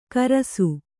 ♪ karasu